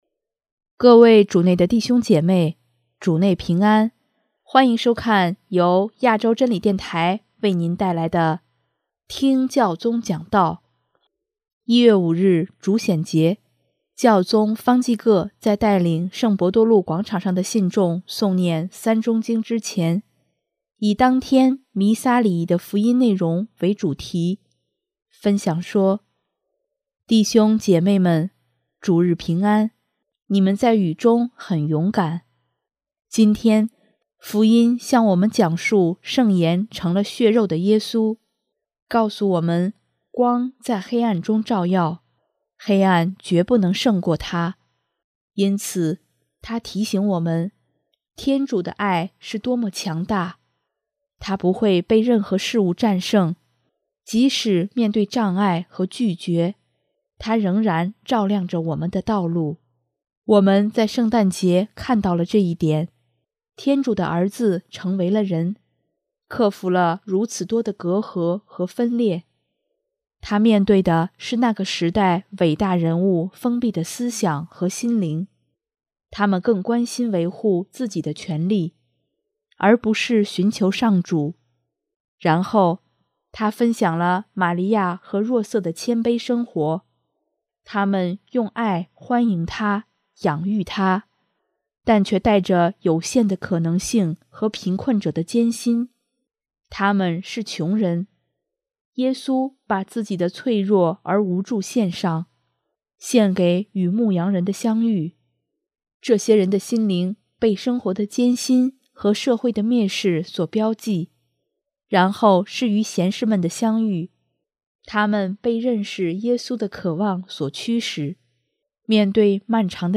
1月5日，主显节，教宗方济各在带领圣伯多禄广场上的信众诵念《三钟经》之前，以当天弥撒礼仪的福音内容为主题，分享说：